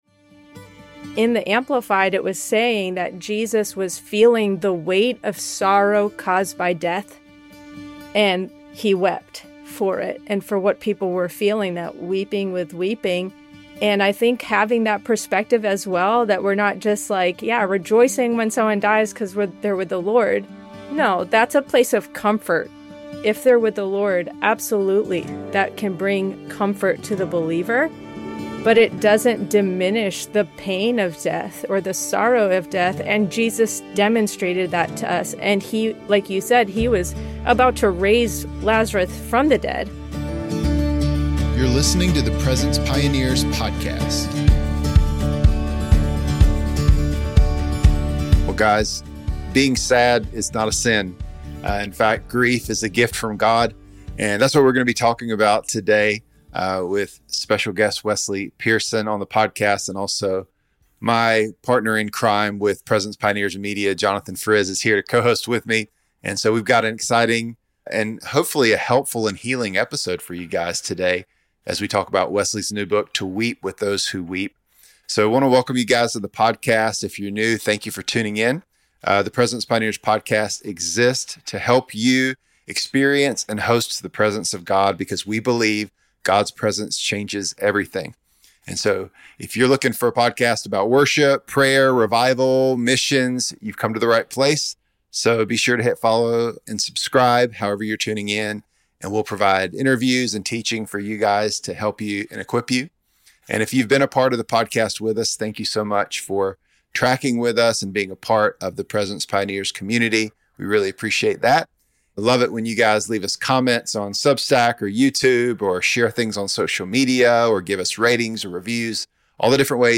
The conversation explores the complexities of grief, faith, and the importance of lament in worship. They discuss personal experiences with waiting and how it tests faith, the significance of community support during grief, and practical approaches to comforting those who are grieving.